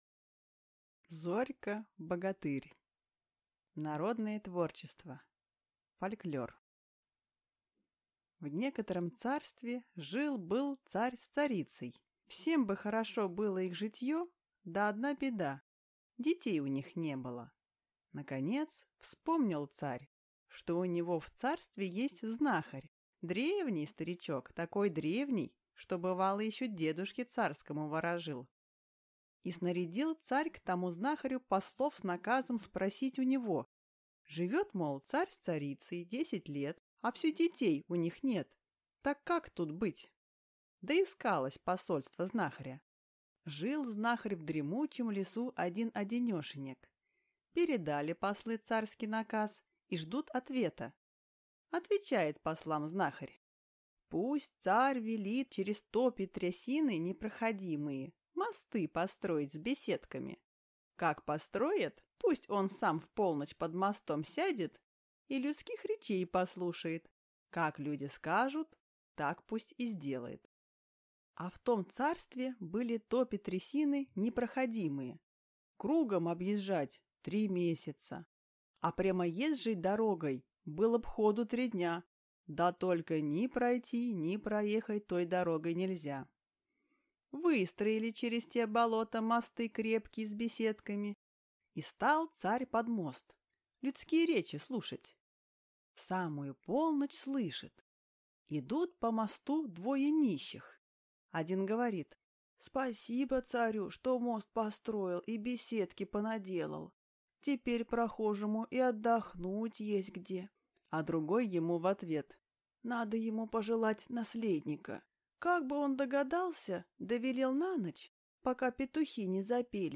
Аудиокнига Зорька-богатырь | Библиотека аудиокниг
Прослушать и бесплатно скачать фрагмент аудиокниги